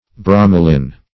Meaning of bromalin. bromalin synonyms, pronunciation, spelling and more from Free Dictionary.
Search Result for " bromalin" : The Collaborative International Dictionary of English v.0.48: Bromalin \Bro"ma*lin\, n. [From Bromine .]